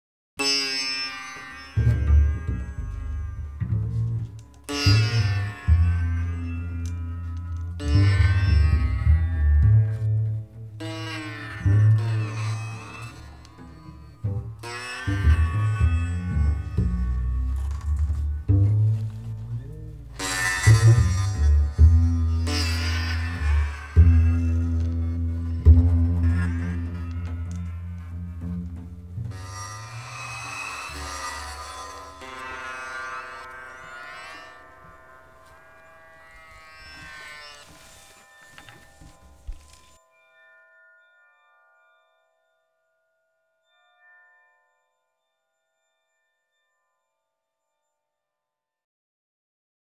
the double bass